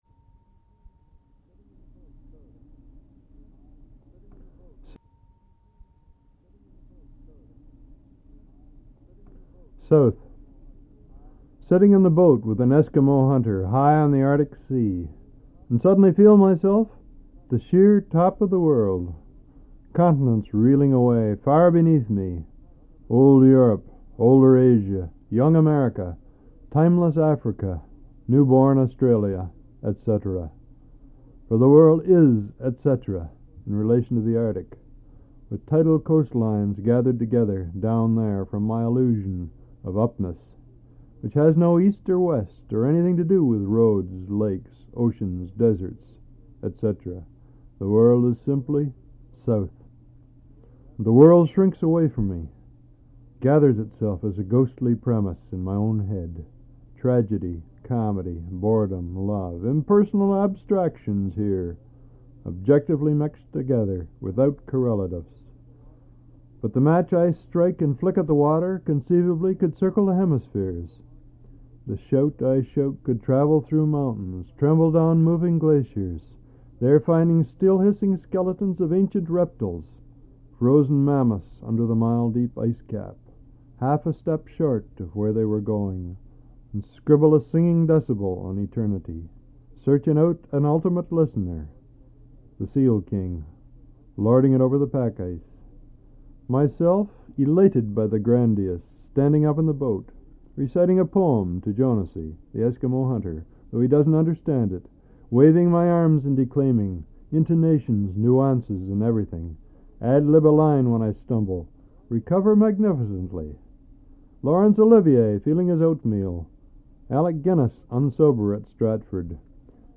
Al Purdy reads his poetry
1/4"' Reel-to-Reel Tape